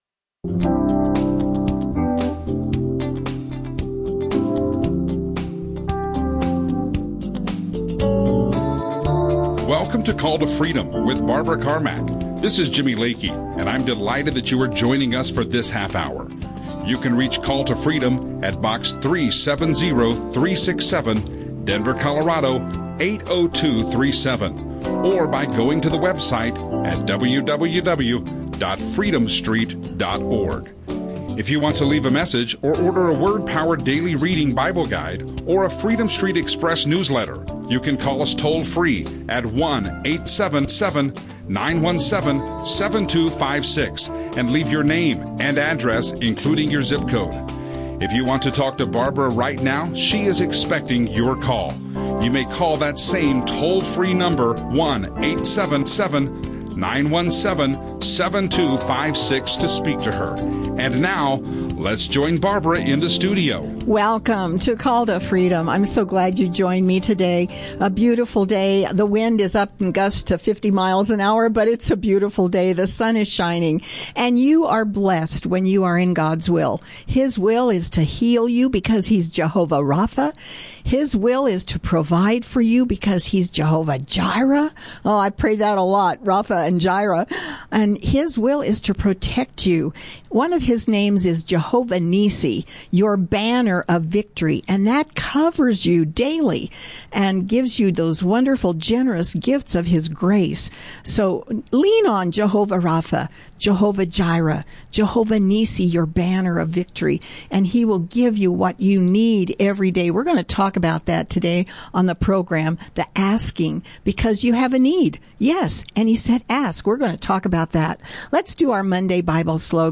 Call to Freedom Christian talk